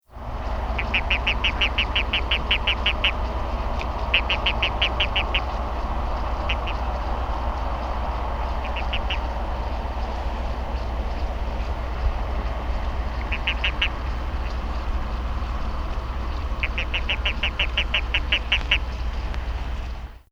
Release Calls
Sound This is a 20 second recording of release calls of a toad (gender not known) that was being amplexed by a male toad at night in a flooded agricultural field in Riverside County, California.